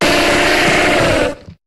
Cri de Gravalanch dans Pokémon HOME.